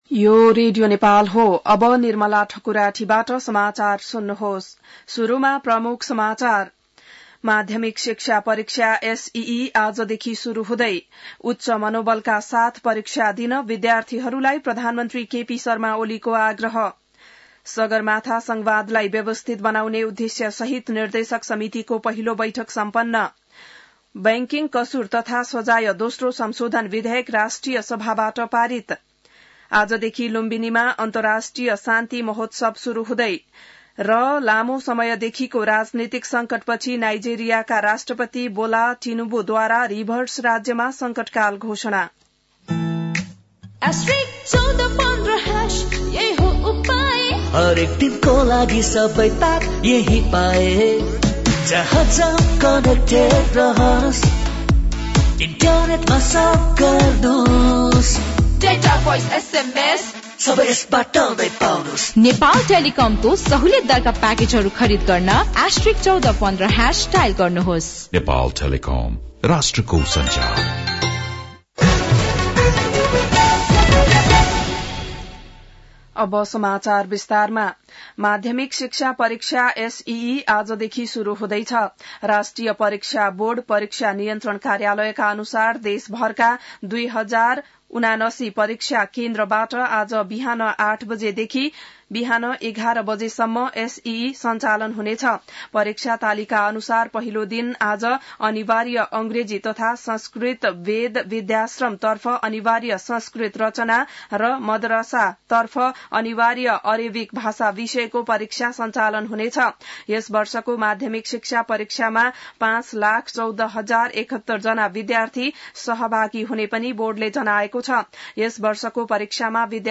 बिहान ७ बजेको नेपाली समाचार : ७ चैत , २०८१